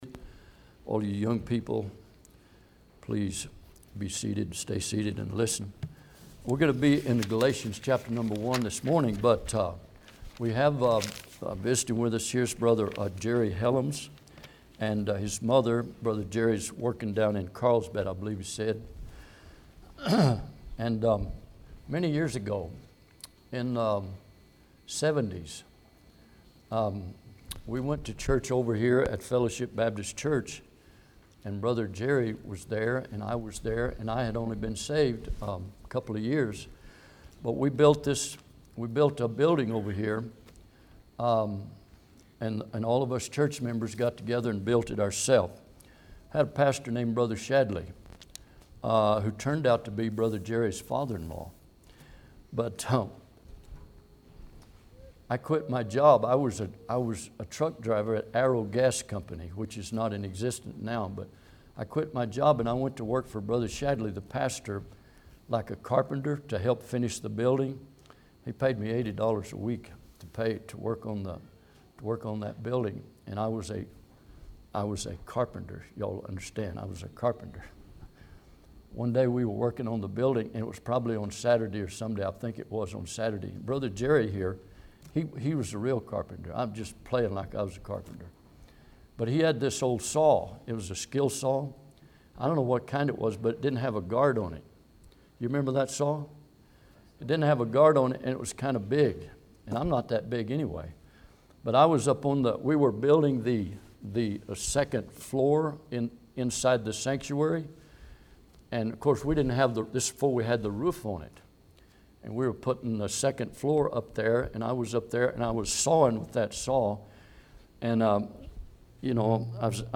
Galatians 1:1-5 Service Type: Sunday am Bible Text